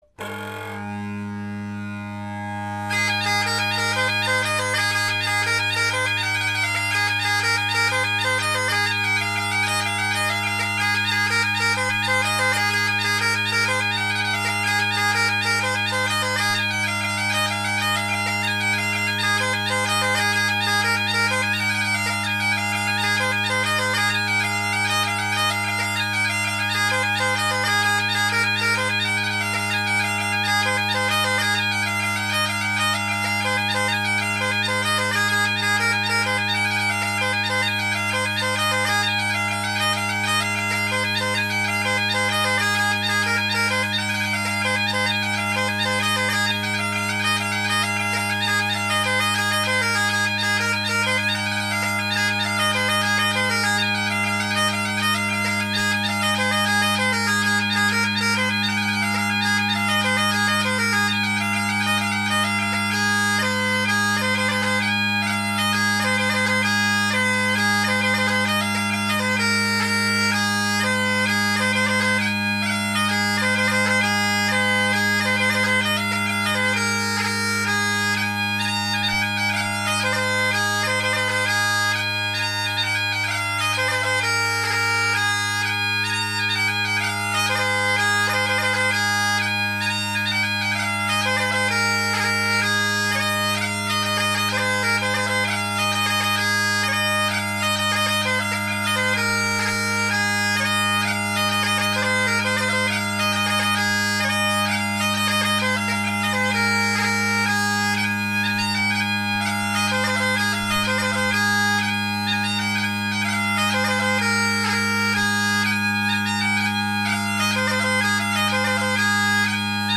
G Major Highland Bagpipes
Bagpipe Sound Research, Great Highland Bagpipe Solo
I have yet again revised my G major highland bagpipe setup which involves tuning the drones to low G instead of low A and then retuning the entire chanter.
My setup has low A tuning around 464 Hz which means my low G is at 413 Hz which is why it’s so hard to get my drones so flat!
My selection criteria were that the tunes focused a lot on low G, high G, B, and D since those harmonize the mostly readily against G drones. Jigs are my bread and butter, so I present to you: